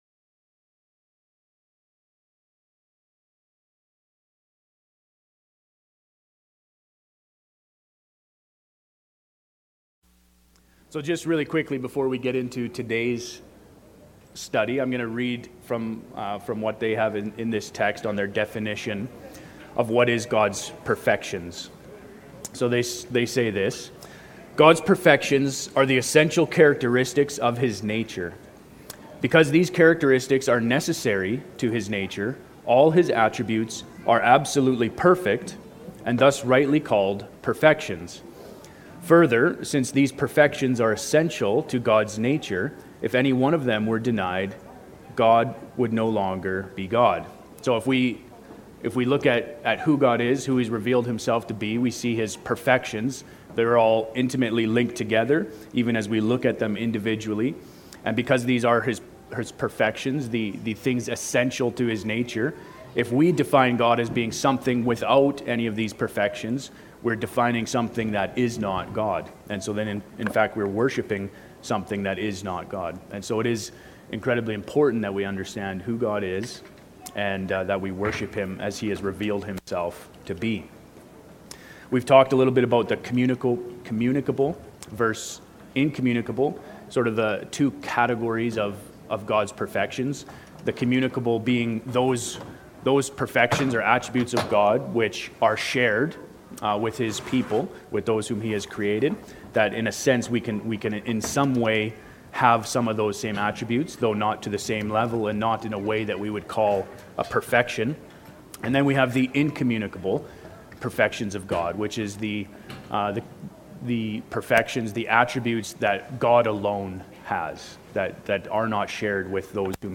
Category: Sunday School